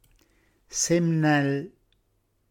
Qui di seguito il nostro repertorio delle parole “reggiane” proprie del nostro dialetto, sia per vocabolo che per significato ad esso attribuito, corredate della traccia audio con la dizione dialettale corretta.